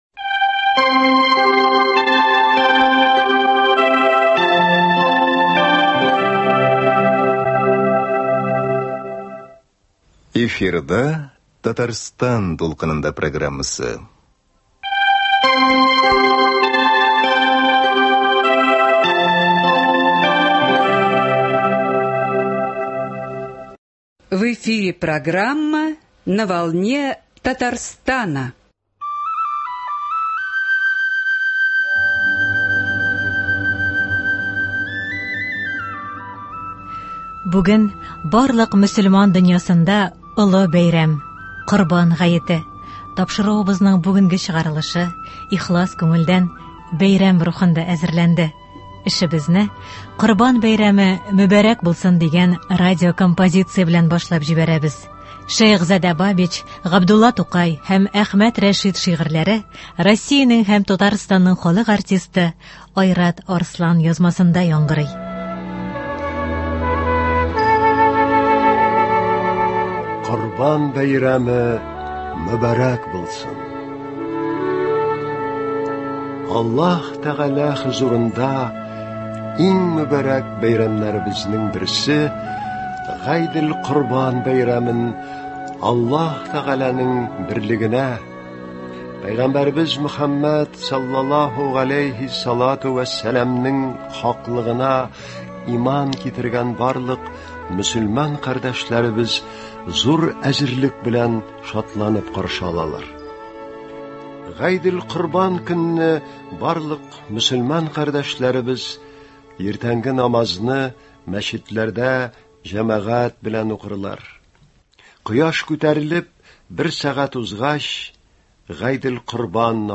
Радиокомпозиция.